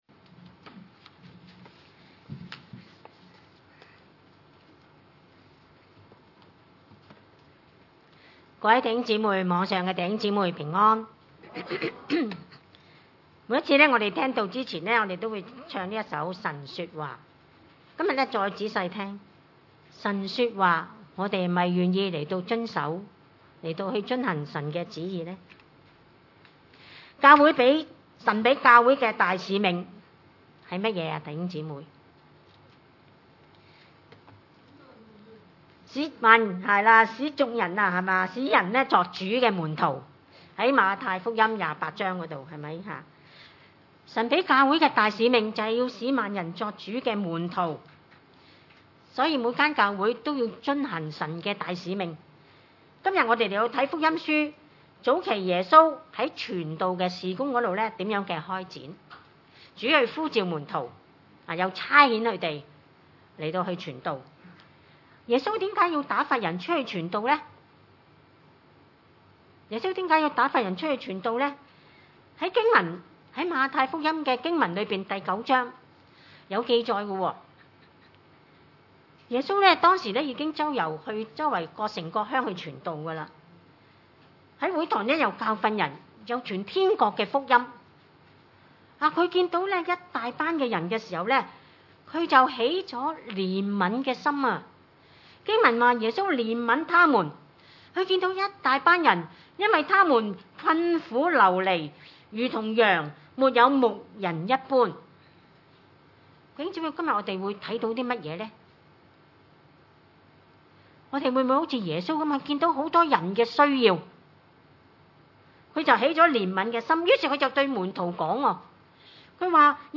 經文: 馬太福音10︰5-39 崇拜類別: 主日午堂崇拜 5.